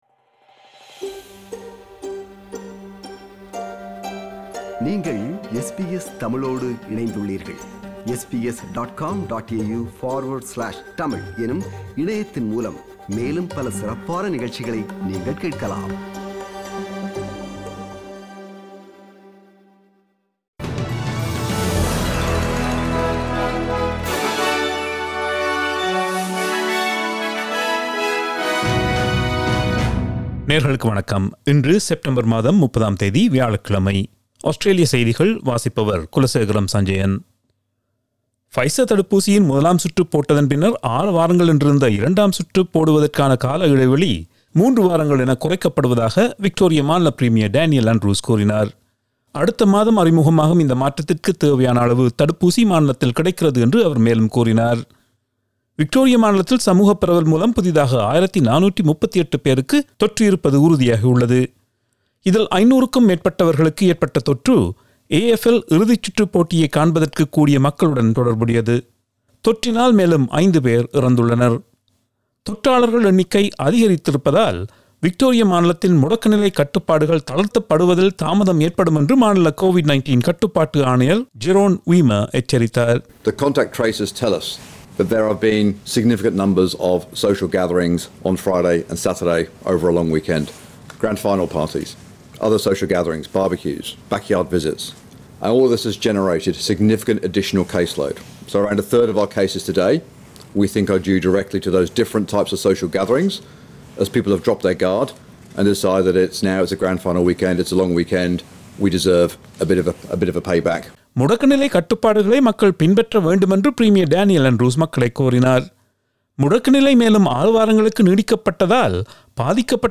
Australian news bulletin for Thursday 30 September 2021.